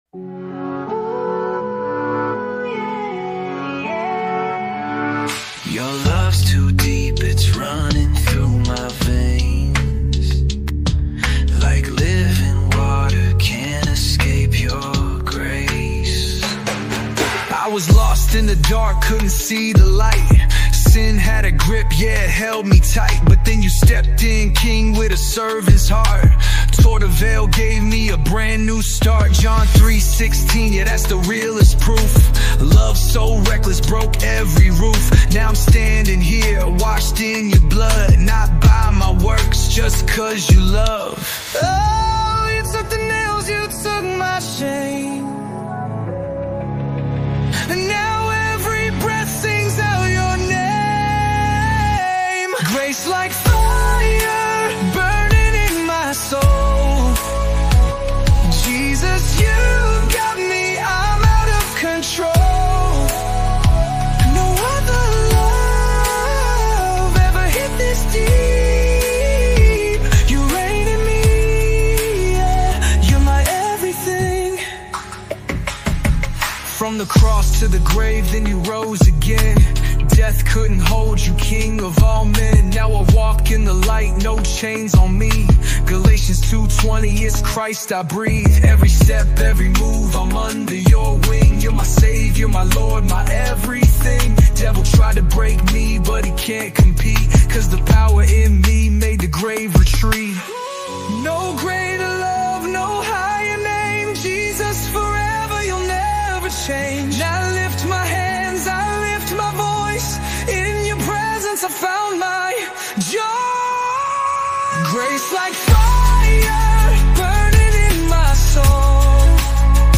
Afro Gospel Music
worship song